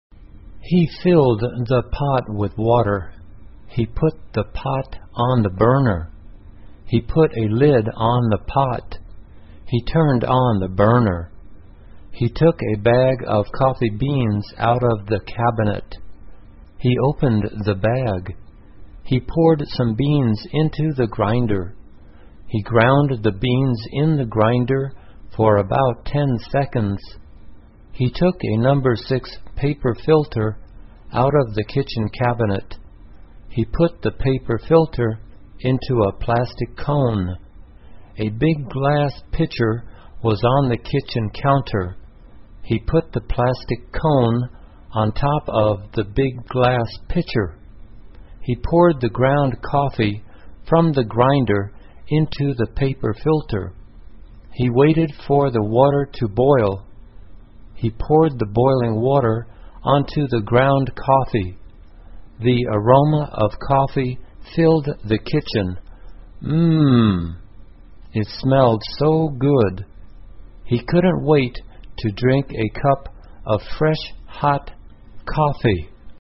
慢速英语短文听力 一杯咖啡 听力文件下载—在线英语听力室